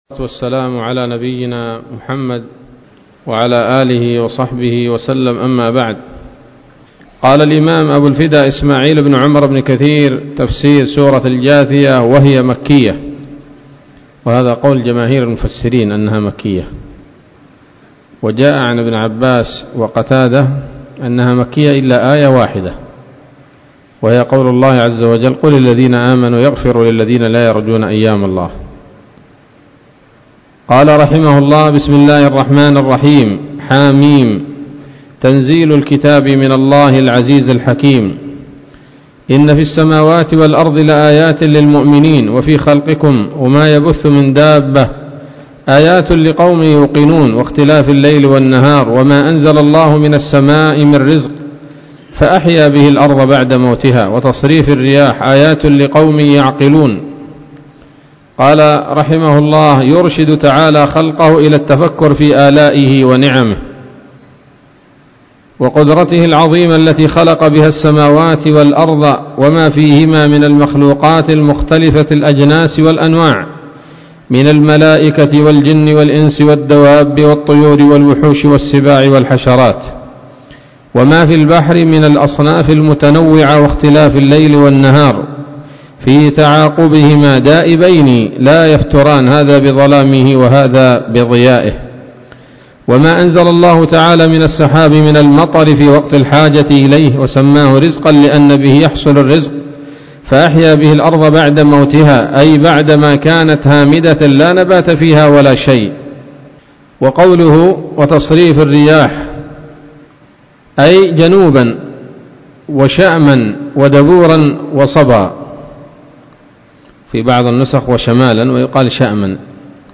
الدرس الأول من سورة الجاثية من تفسير ابن كثير رحمه الله تعالى